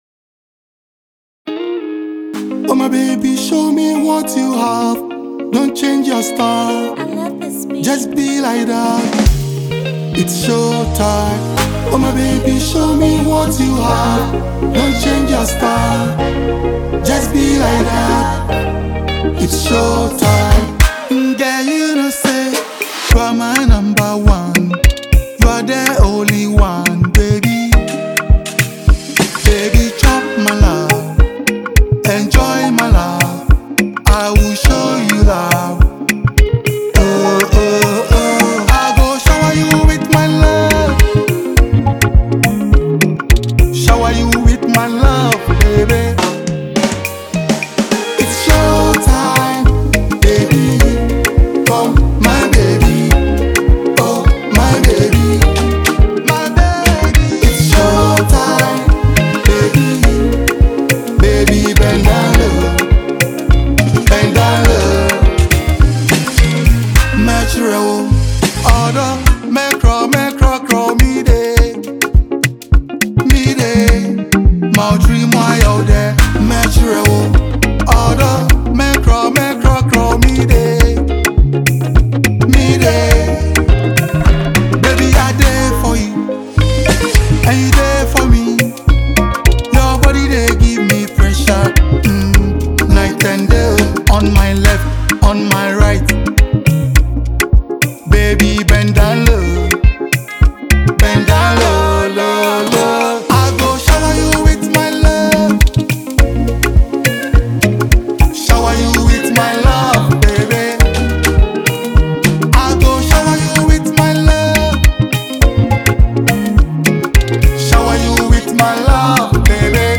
This Afrobeat/Highlife song
With its infectious rhythm and melodic hooks